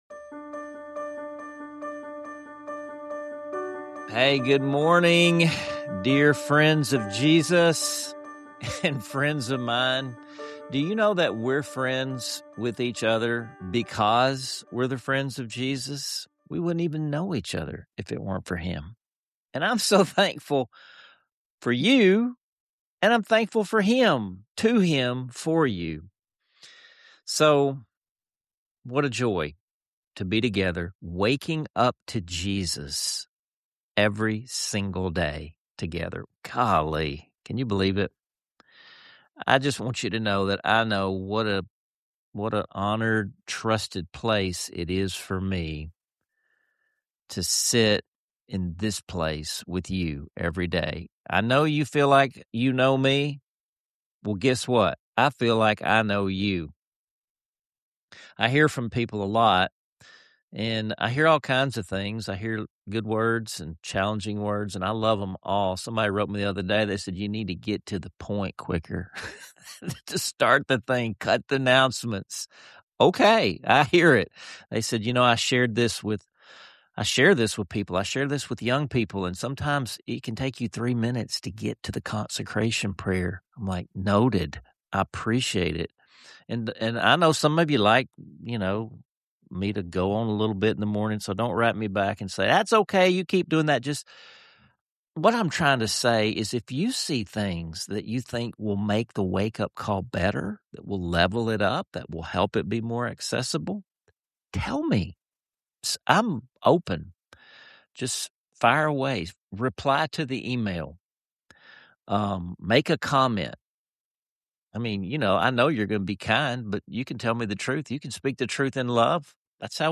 An uplifting hymn medley and a joyful exploration of why lifting your hands in worship might just change your perspective.